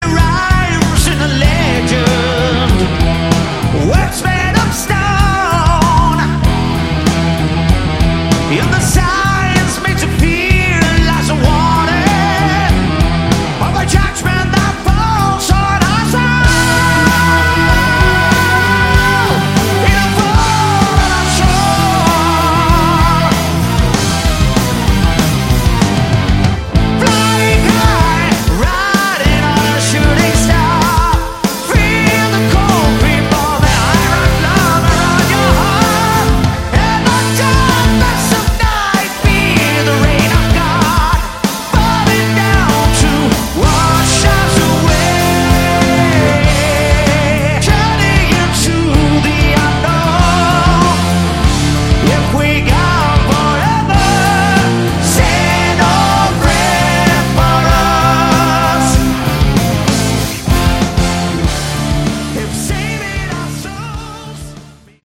Category: Melodic Metal
vocals
guitar
bass
drums
keyboards